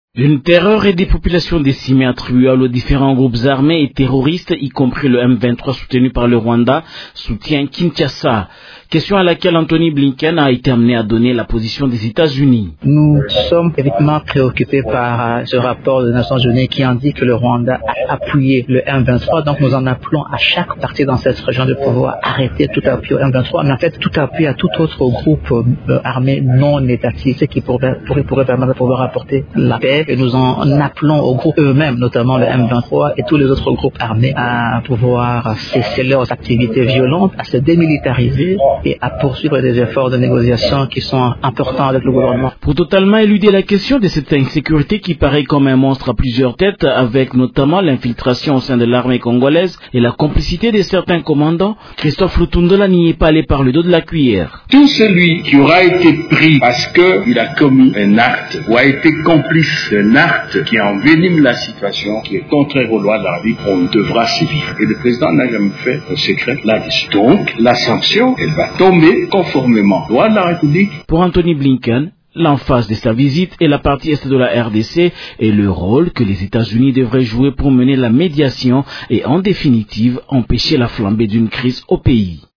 « Nous sommes très préoccupés par les informations crédibles selon lesquelles le Rwanda a soutenu le M23», a déclaré mardi 9 août à Kinshasa le Secrétaire d'Etat américain, Antony Blinken, au cours d’une conférence de presse coanimée avec le vice-premier ministre, ministre des Affaires étrangères, Christophe Lutundula.